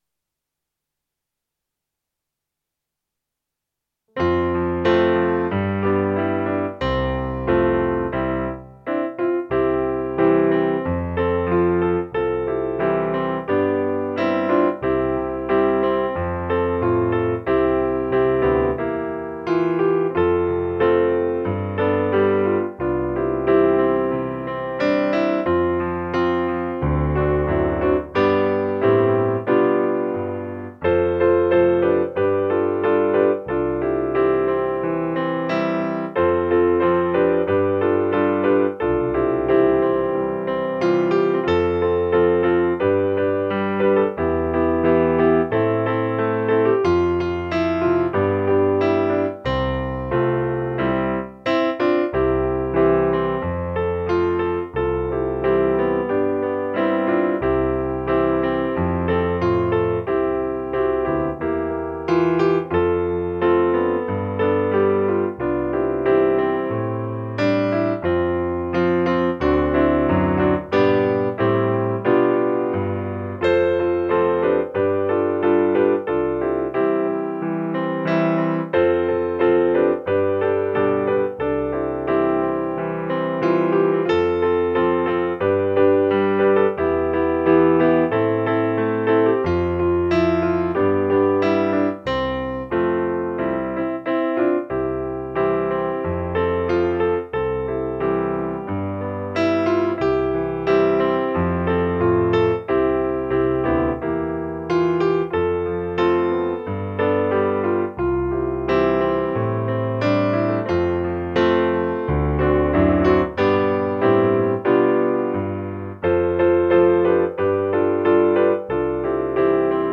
Po wręczeniu nagród chór szkolny